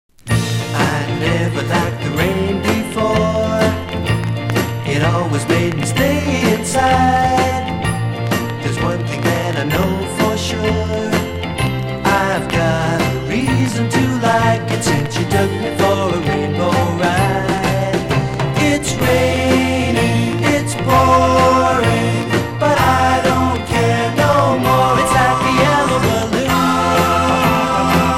彼等のアルバムのジャケもそうだったが重要な要素・・・爽やかな日光、青春メロディ、適度なビート等々、なるほど揃っている。
(税込￥1980)   SUNSHINE POP